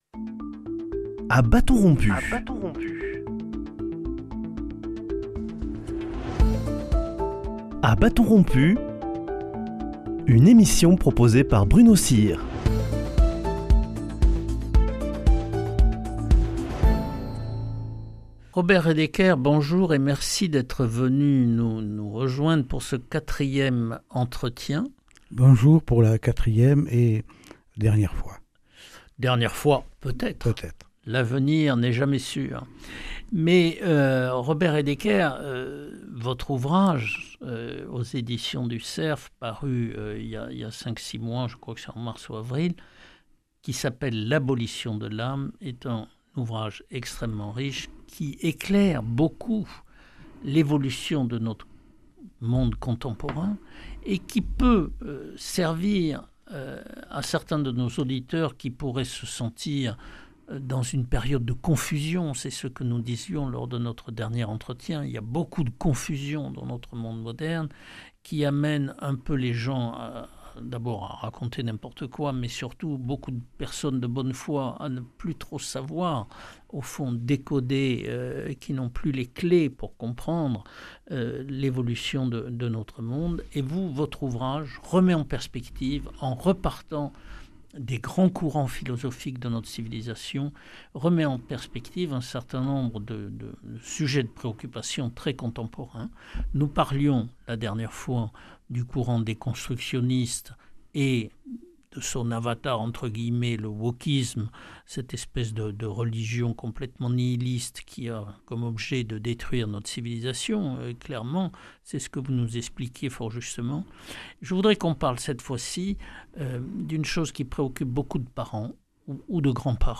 [ Rediffusion ] Robert REDEKER, un philosophe très prolifique, nous parle de son dernier ouvrage « L’abolition de l’âme ». Il nous amène au fil des entretiens à considérer qu’il faut tourner le dos à l’idéologie mortifère de la déconstruction, à la théorie du genre et à son avatar l’écriture inclusive pour, au contraire, « construire » ou « reconstruire » les repères dont nous avons besoin. Il voit dans le message des grands philosophes chrétiens une source d’espérance qui éclaire le monde complexe qui nous entoure.